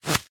Minecraft Version Minecraft Version latest Latest Release | Latest Snapshot latest / assets / minecraft / sounds / entity / leashknot / place2.ogg Compare With Compare With Latest Release | Latest Snapshot